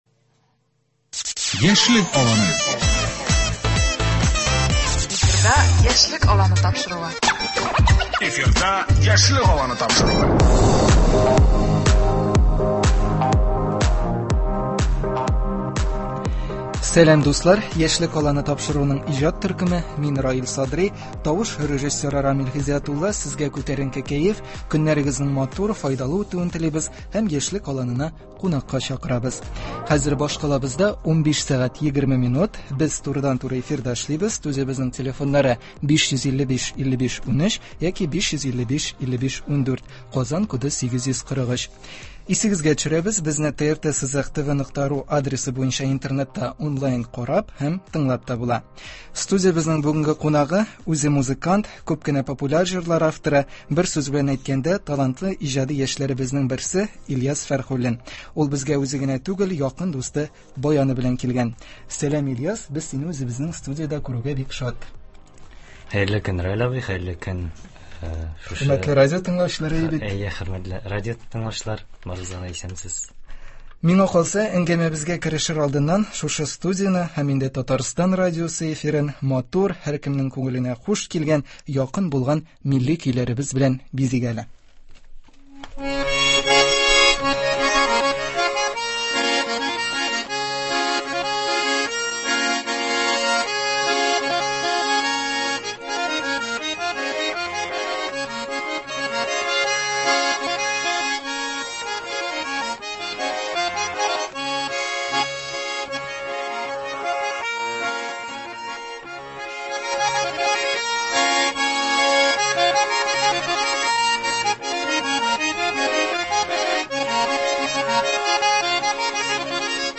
Ул безгә үзе генә түгел, якын дусты – баяны белән килгән.